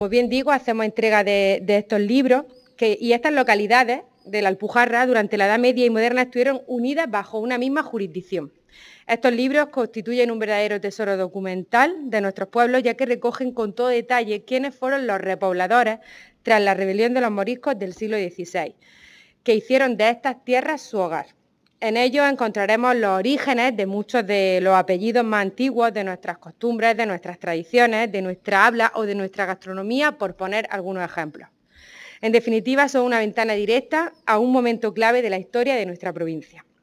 En el acto de entrega, que se ha realizado en el Área de Cultura de la Diputación de Almería, la vicepresidenta y diputada de Cultura, Cine e Identidad Almeriense, Almudena Morales, ha entregado los tomos a los alcaldes y concejales de estos municipios.